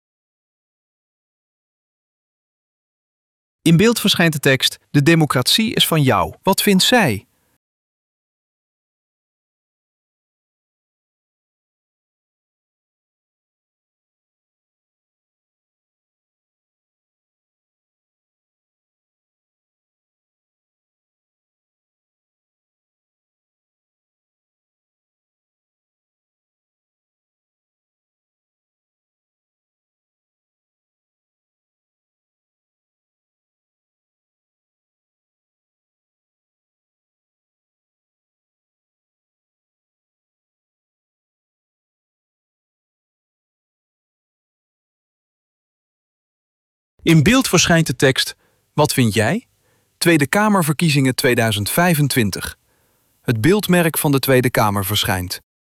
Audiodescriptie Video 2.mp3